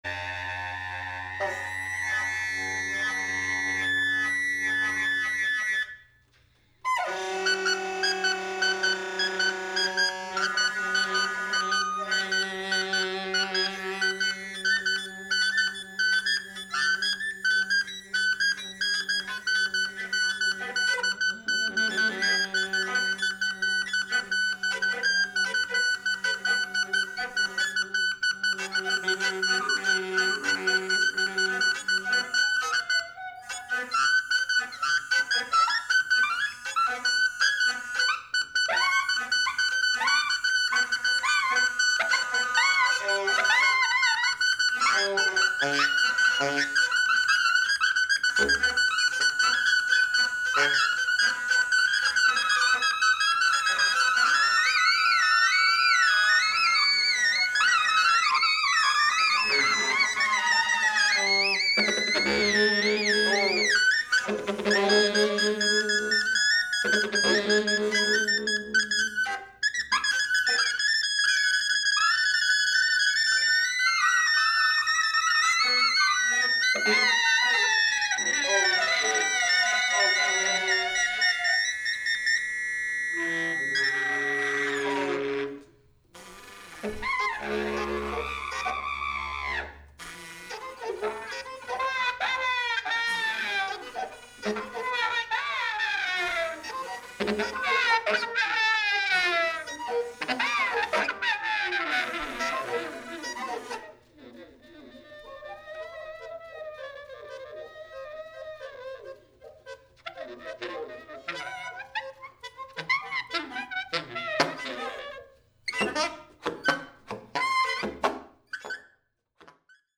Volume 1, Cafe OTO 9th July 2022